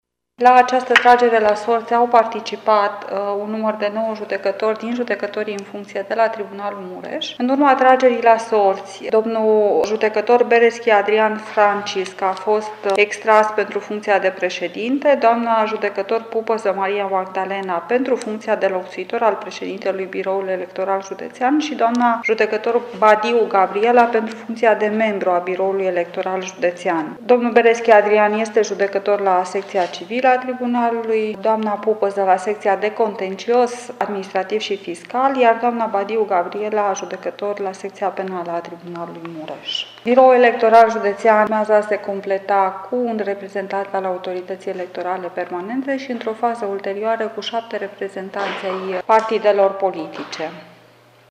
Vicepreședintele Tribunalului Mureș, judecător Teodora Albu: